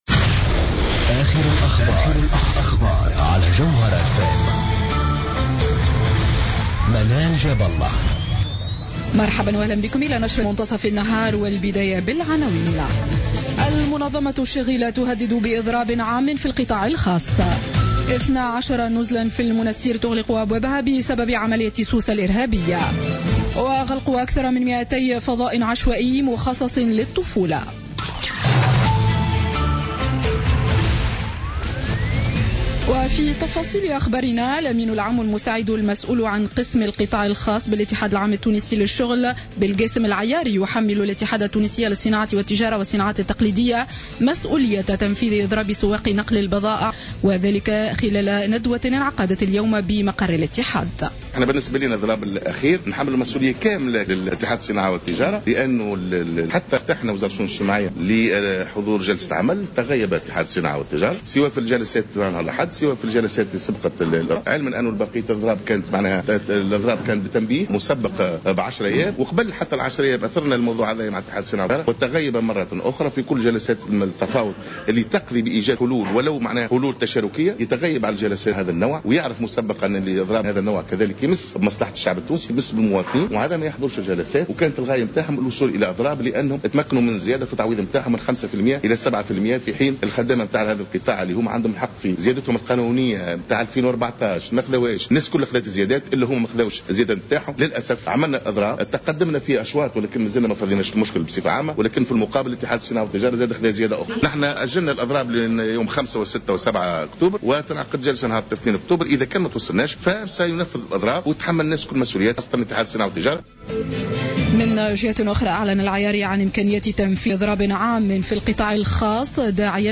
نشرة أخبار منتصف النهار ليوم الثلاثاء 22 سبتمبر 2015